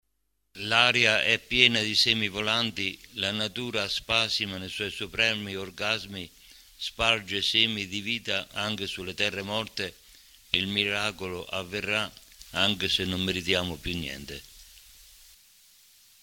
La registrazione è del 2004 ed è stata effettuata dai ragazzi dell’IPC di Cupra Marittima e dai loro insegnanti per Dream Radio Stream, la loro web radio, fu la prima in Italia di un istituto d’istruzione superiore. Le letture riguardano delle brevi poesie e alcuni aforismi.